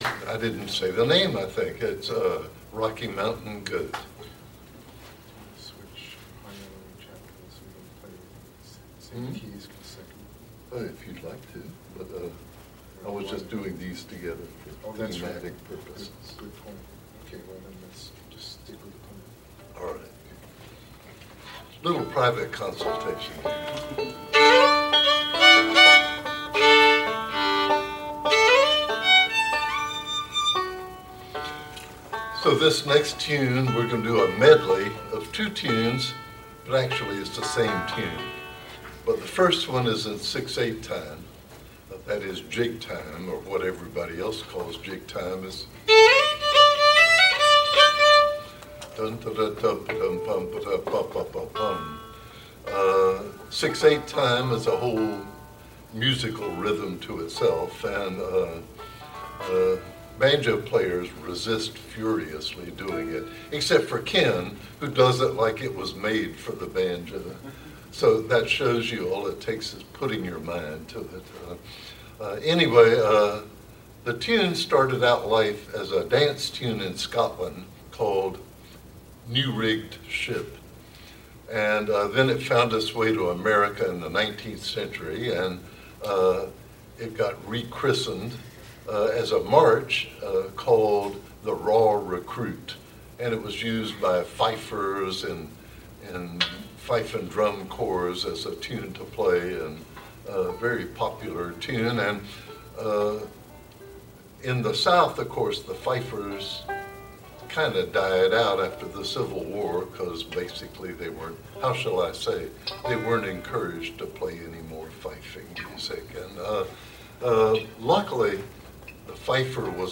Live Performance